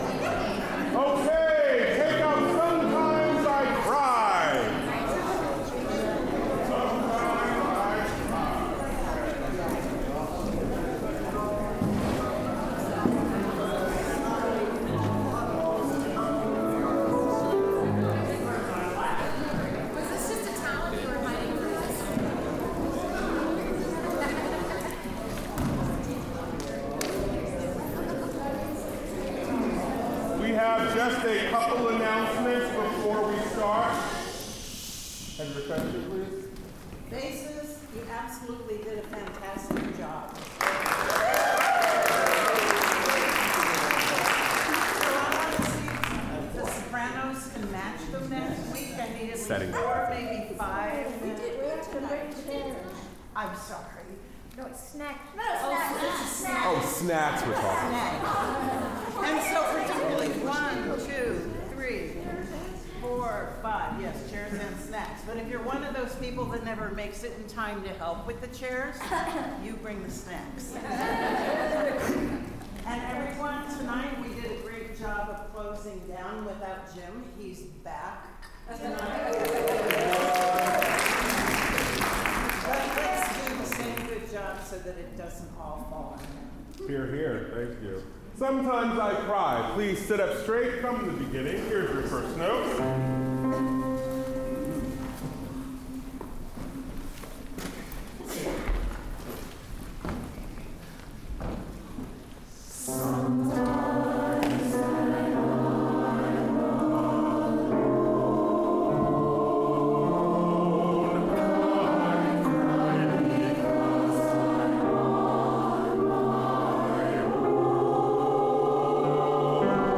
OSC Rehearsal, 12 March 2025
0:00 Warmups including solfege intervals